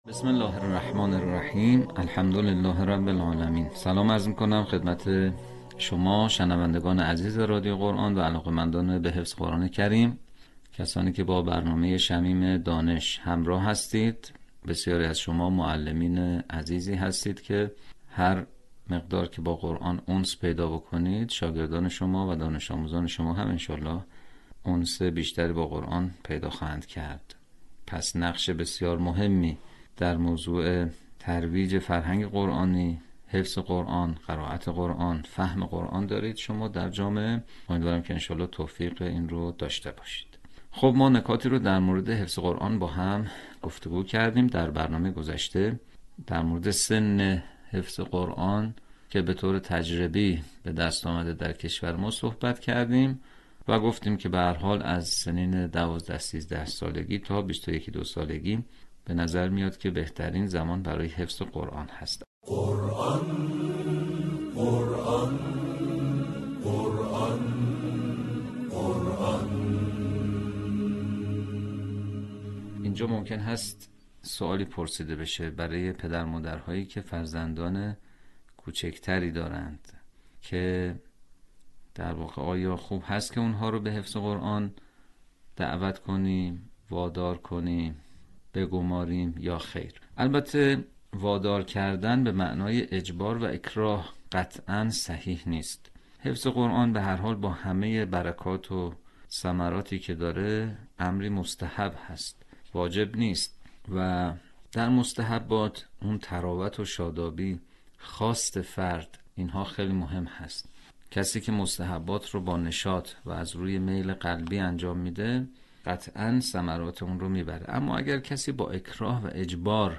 به همین منظور مجموعه آموزشی شنیداری(صوتی) قرآنی را گردآوری و برای علاقه‌مندان بازنشر می‌کند.